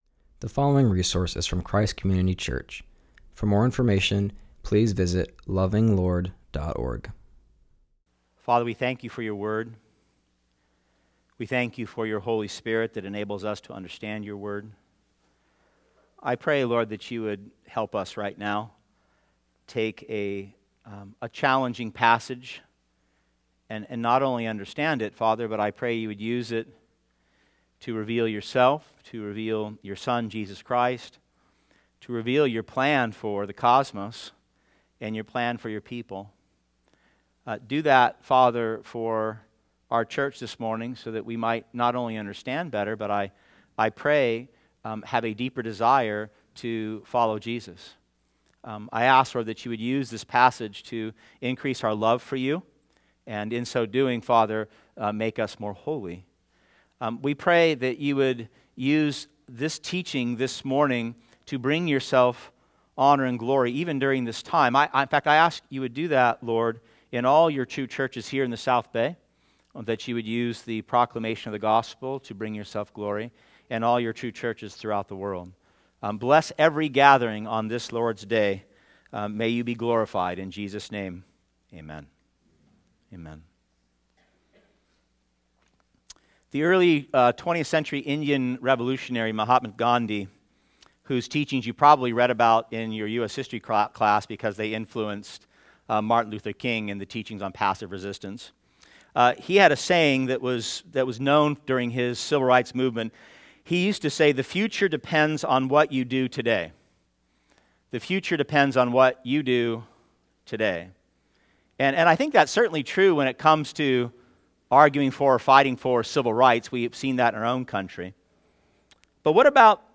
continues our series and preaches from Ephesians 1:7-10.